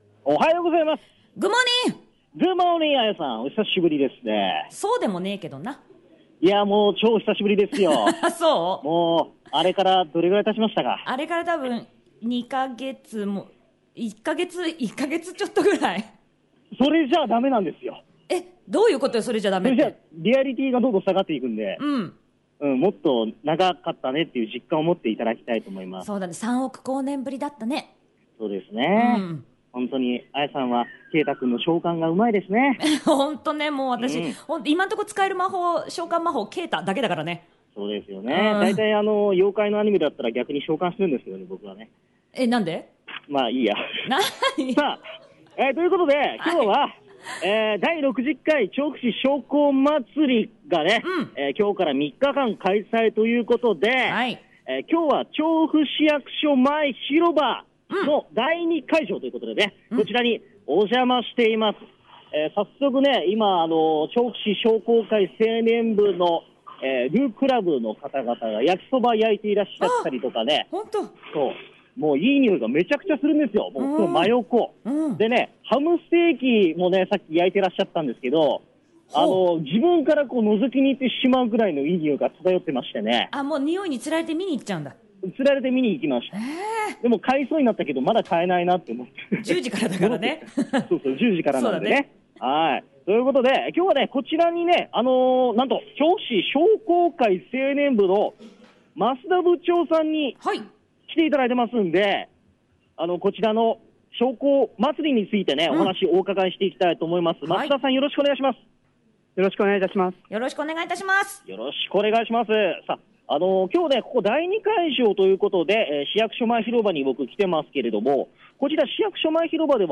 第一会場が調布駅前南口広場！第二会場が市役所前広場・むらさきホールとなっています！